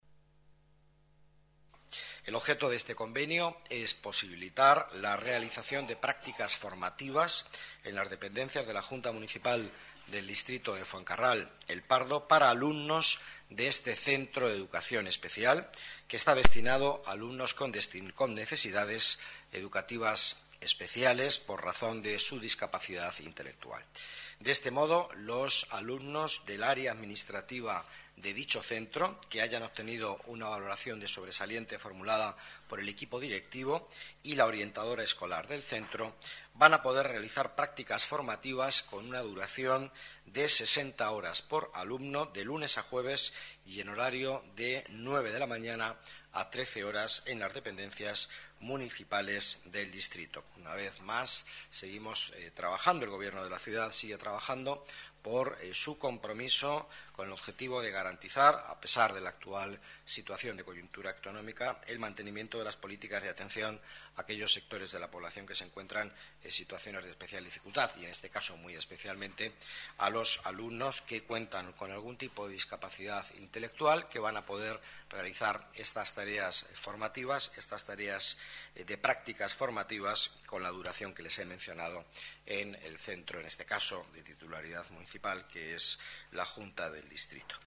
Nueva ventana:Declaraciones vicealcalde Madrid, Miguel Ángel Villanueva: convenio para prácticas alumnos educación especial